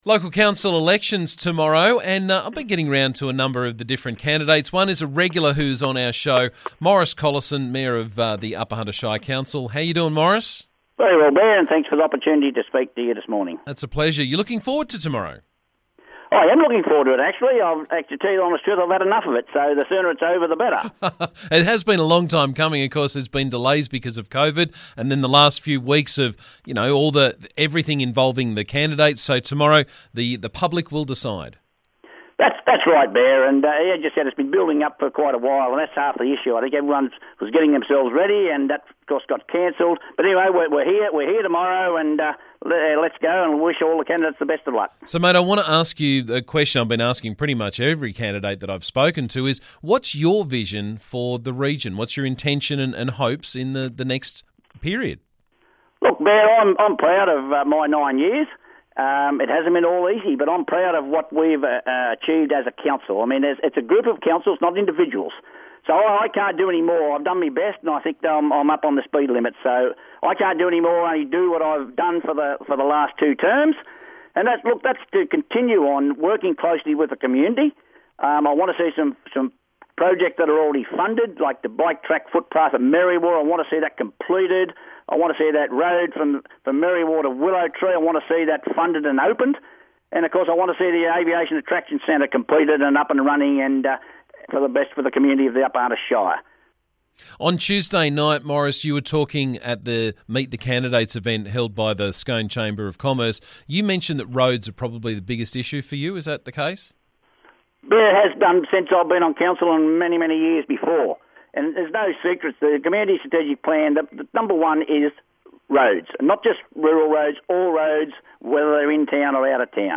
Upper Hunter Shire Council Mayor Maurice Collison is regularly on the show to keep us up to date but this morning we discussed his candidacy in tomorrows election.